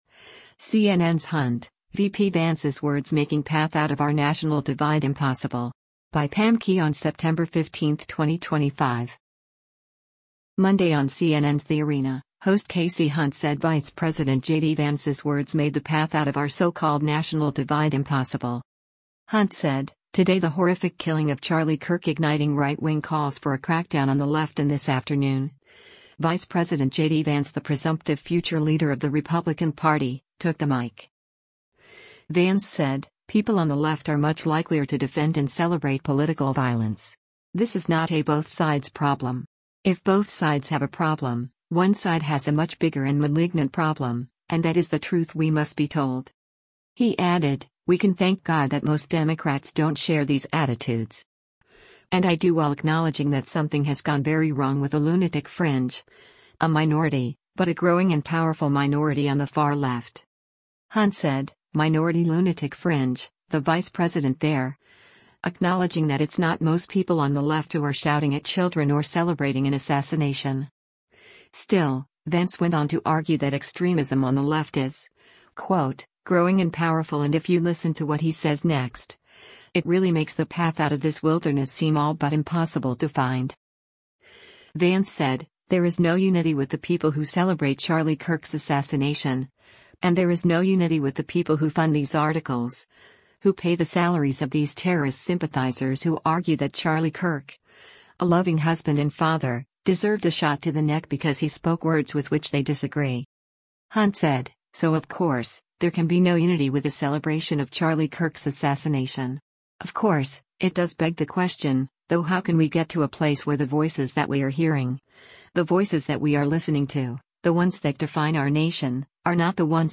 Monday on CNN’s “The Arena,” host Kasie Hunt said Vice President JD Vance’s words made the path out of our so-called national divide “impossible.”